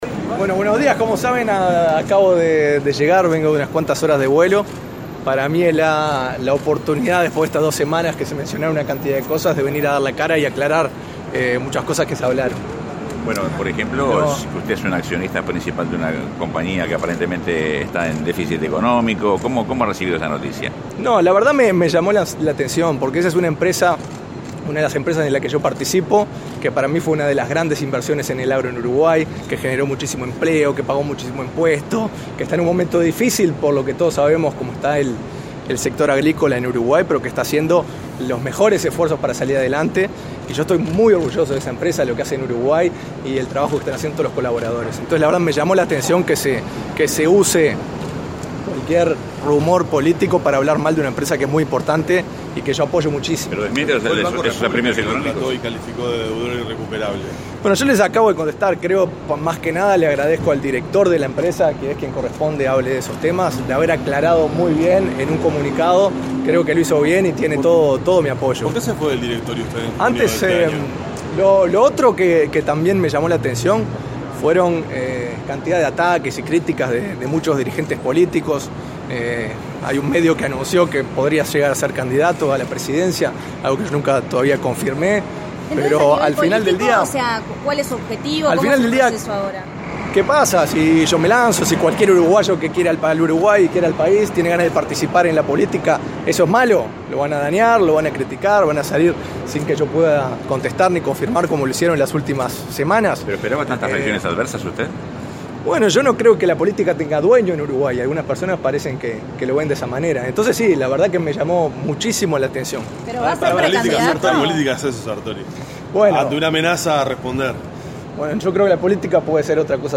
Sartori arribó este mediodía dejando sin contestar la pregunta de los periodistas apostados en el lugar acerca de si va a ser o no precandidato por filas nacionalistas.